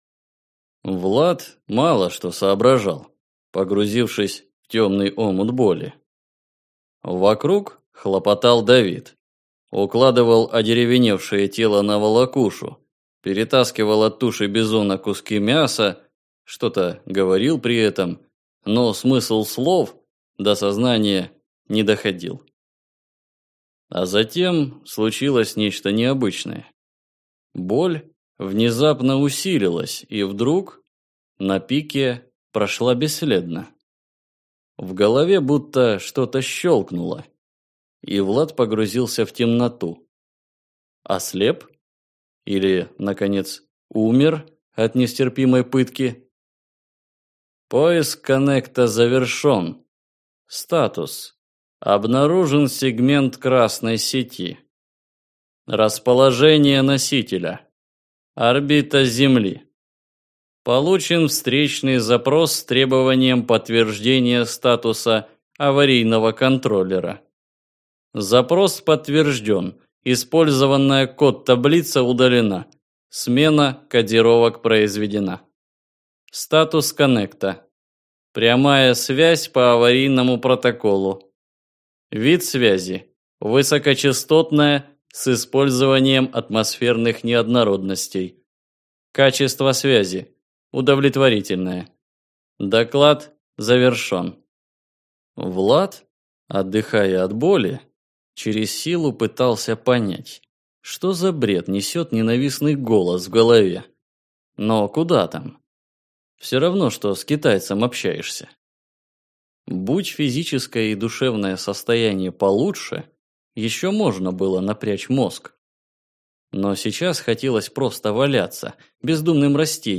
Аудиокнига Холод юга - купить, скачать и слушать онлайн | КнигоПоиск